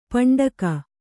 ♪ paṇḍaka